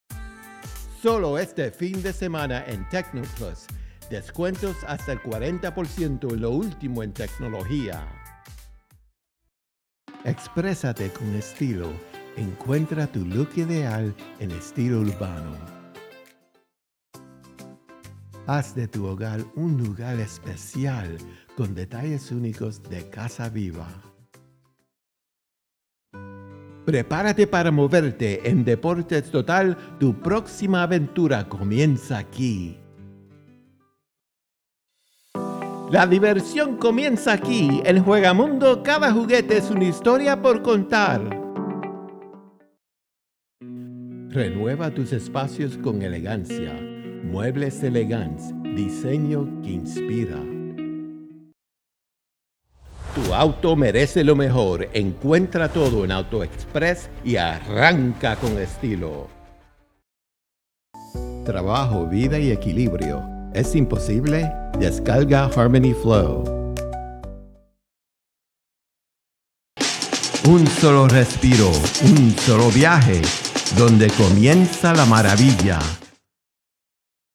Spanish Commercial (1:17)
Dynamic bilingual delivery featuring: Electrónica, Ropa Juvenil, Hogar, Deportes y Aire Libre, Juguetería, Muebles, and Accesorios para Auto.
All content recorded in Studio 23, Nashville, Tennessee.
Spanish-Commercial-Reel.mp3